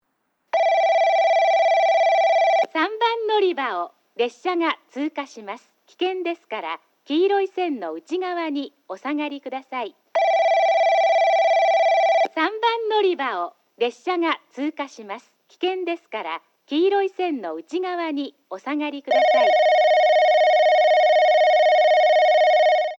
スピーカーはJVCラインアレイですが、設置数が多いので収録が行いやすくなっています。
3番のりば通過放送　女声